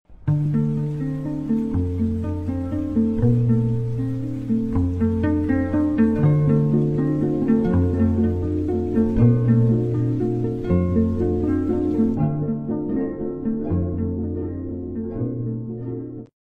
As Risadinhas Deles